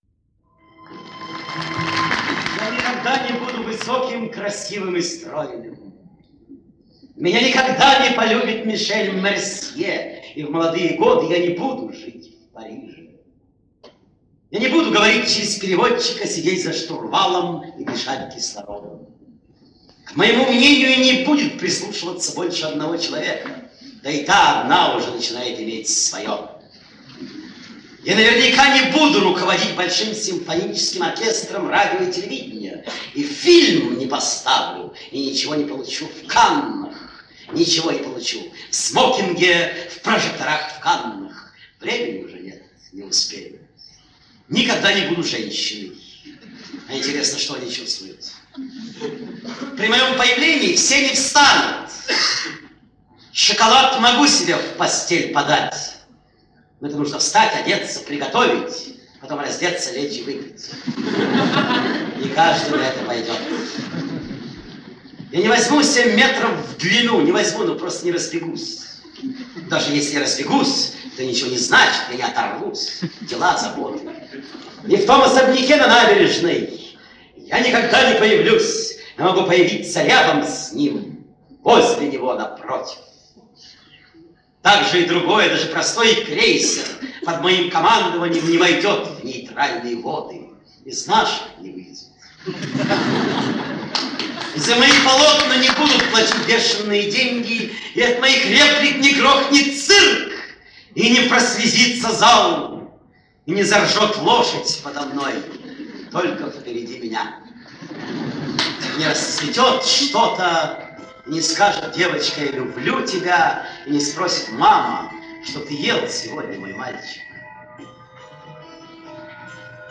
Прошу помочь Роман Карцев монолог Автопортрет